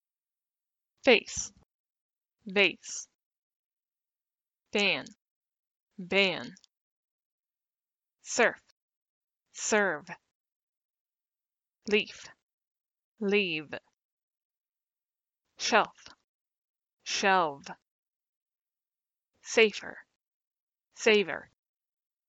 Home Pronunciation Checker Academy American English Sounds - /f/ voiceless, labiodental, fricative consonant
However, /f/ is voiceless and /v/ is voiced.
You can hear the difference between /f/ and /v/ in these words.
compare_f-v_words.mp3